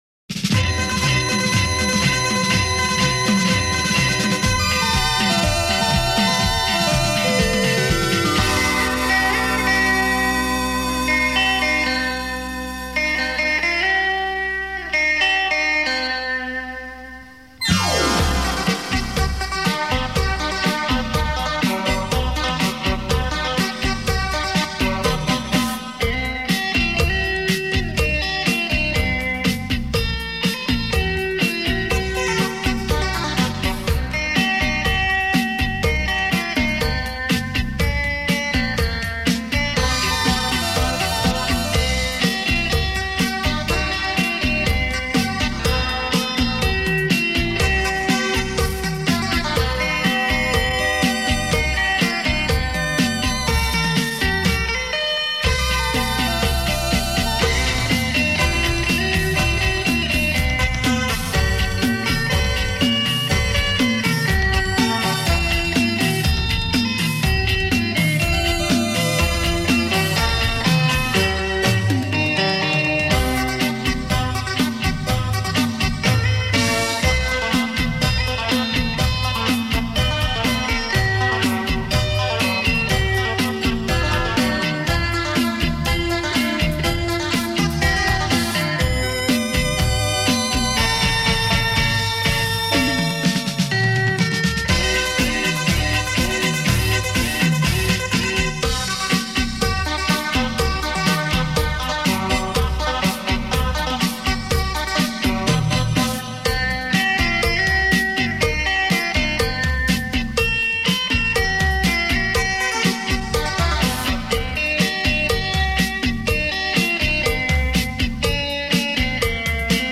缠绵的电子舞曲娓娓响起，昏暗的舞厅再叙离别之情。
磁带数字化